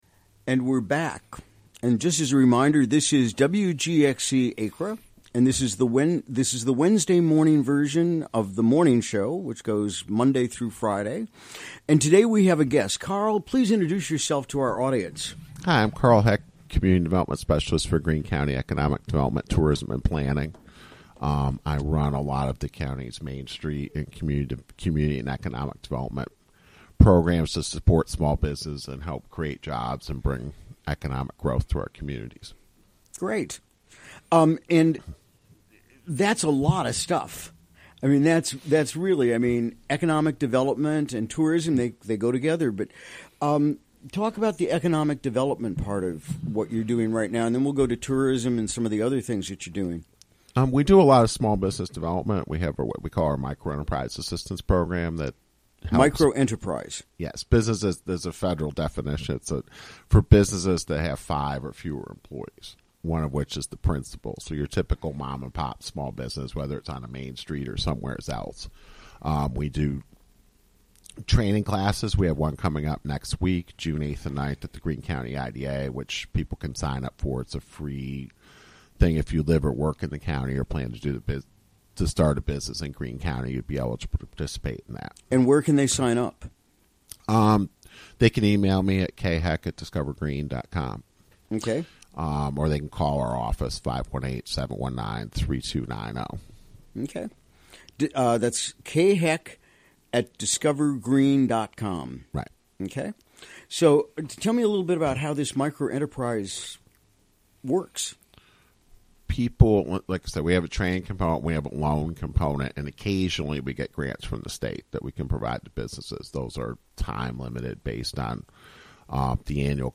Recorded during the WGXC Morning Show on Wednesday, May 31.